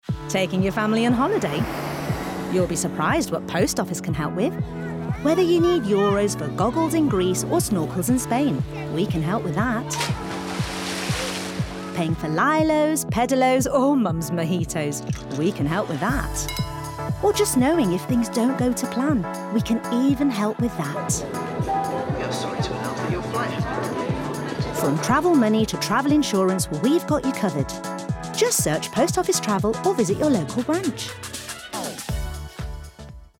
40/50s, Midlands/Indian/RP, Warm/Versatile/Experienced
• Commercial